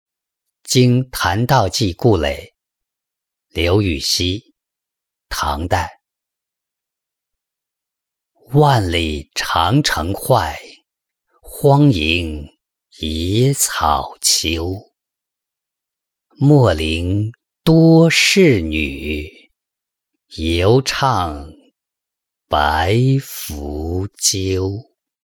经檀道济故垒-音频朗读